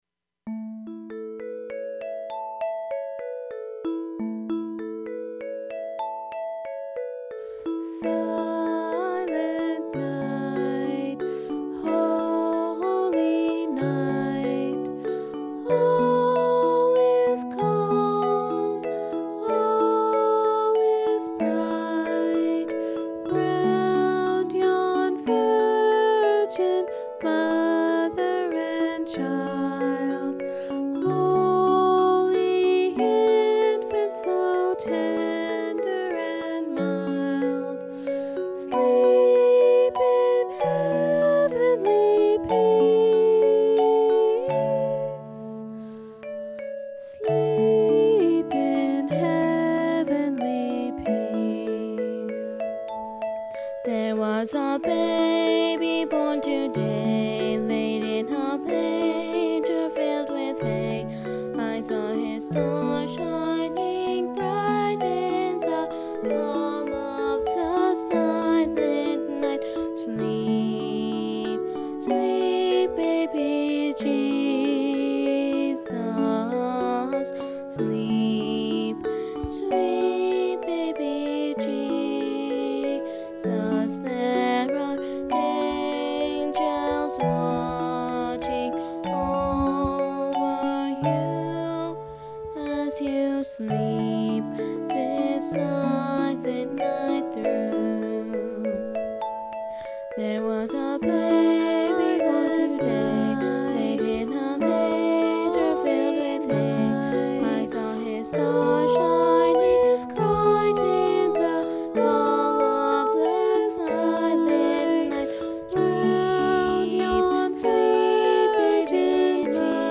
silent-night-duet.mp3